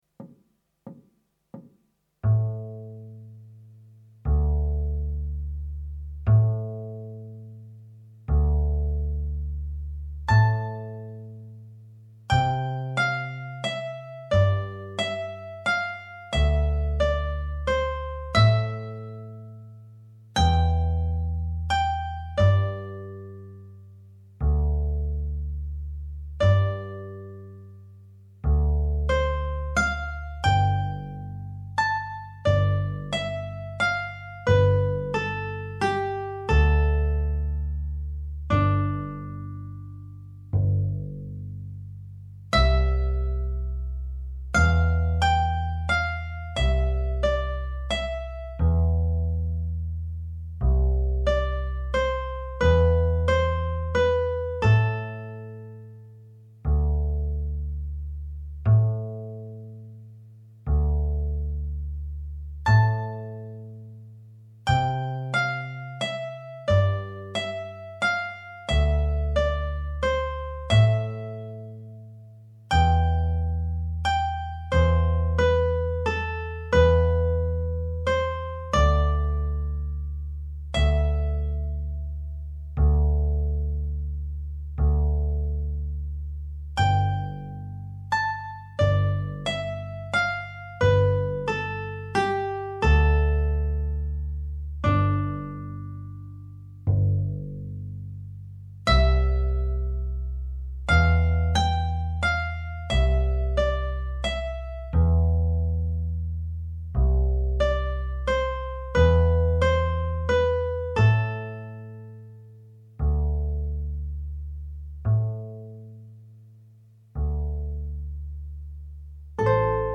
minus Guitar 3